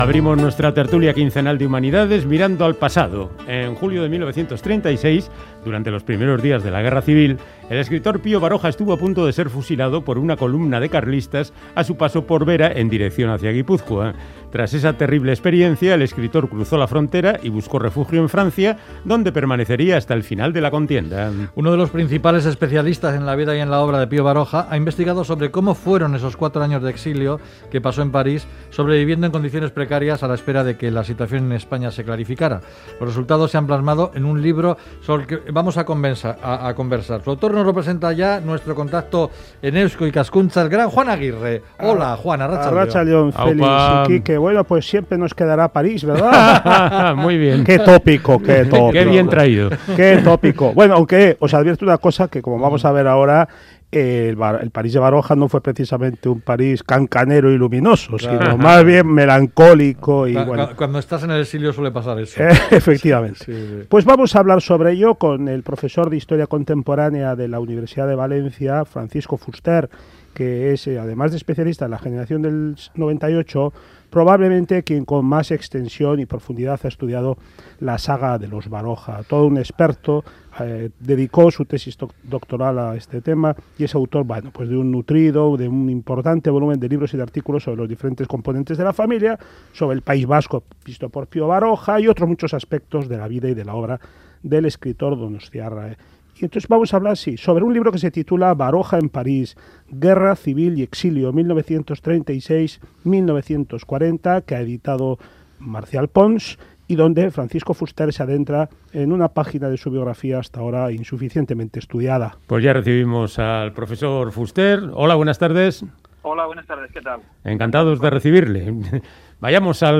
Los resultados se han plasmado en un libro sobre el que vamos a conversar.
irratsaioa_ifl_piobaroja.mp3